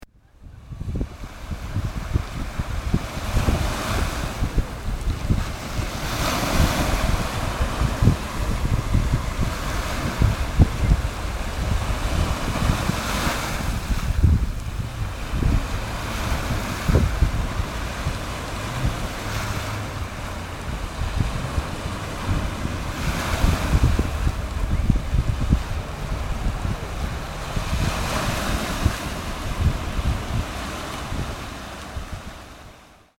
The difference in sound level is extraordinary. It is even possible to hear the waves washing across the sand (sorry about the wind noises)...
waves.mp3